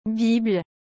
Como se pronuncia Bible corretamente em francês?
A pronúncia de Bible em francês é /bibl/, bem curta e sem o som do “e” final.
1. Comece com um “B” bem suave.
2. Faça um som curto de “i”, parecido com o de “livro” em português.
3. Finalize com um “bl” seco, sem exagerar no sopro de ar.